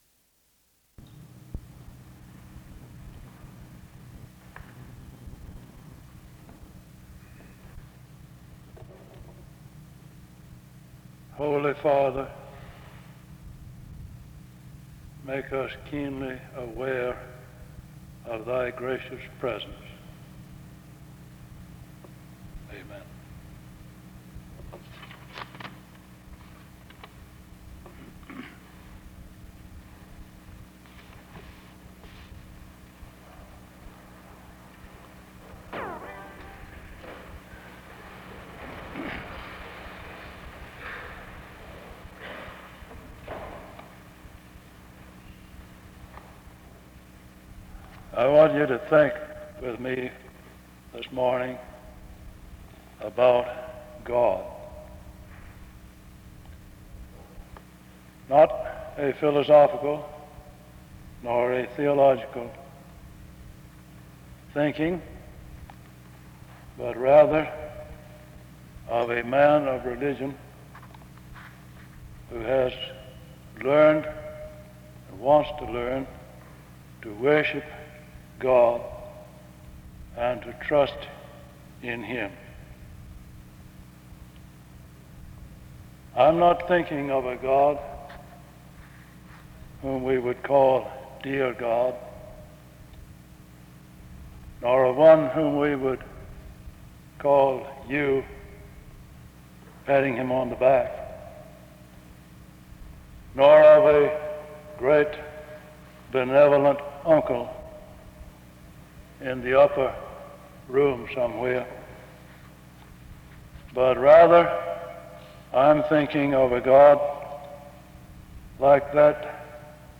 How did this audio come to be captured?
He then closes with a hymn, of which the audio is cut out (14:36-14:39), and a recitation of the Lord’s Prayer from Matthew 6:9-13 (14:40-15:13).